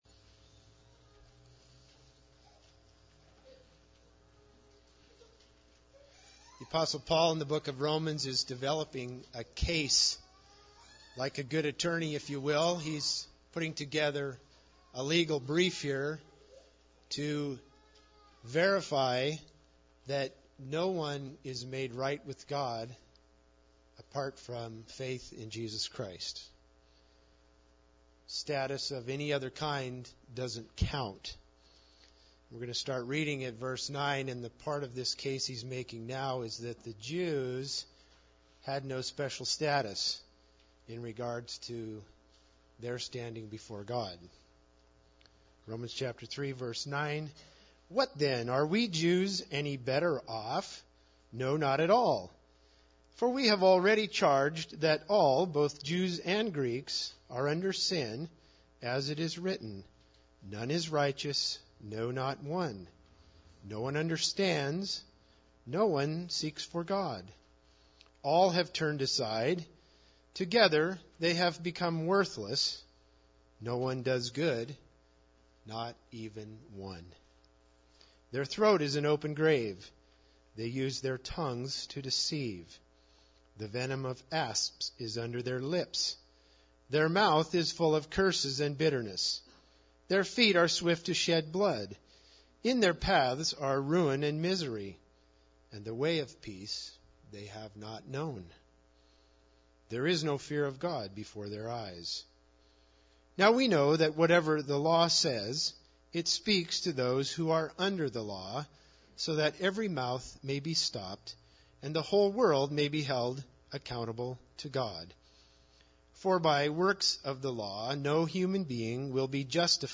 Romans 3:9-31 Service Type: Sunday Service Bible Text